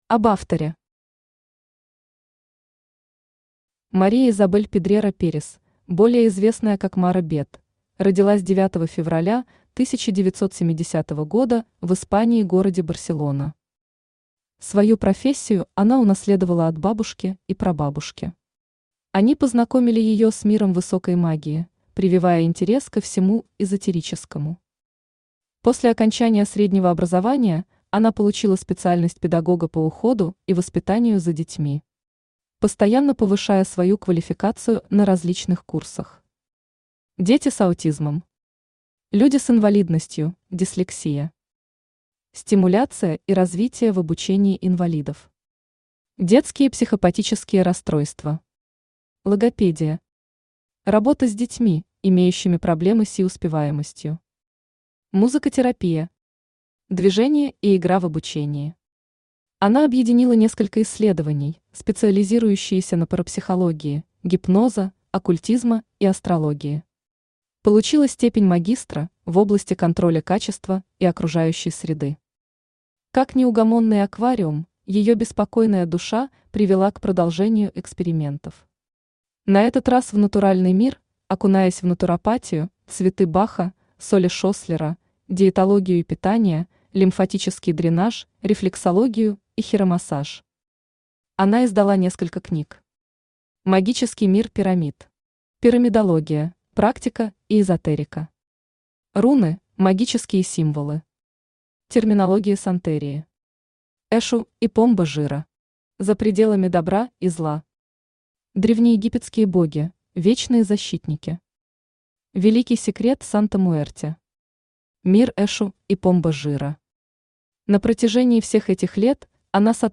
Аудиокнига Древние египетские боги, вечные защитники | Библиотека аудиокниг
Aудиокнига Древние египетские боги, вечные защитники Автор Maribel Pedrera Pérez – Maga Beth Читает аудиокнигу Авточтец ЛитРес.